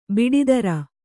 ♪ biḍi dara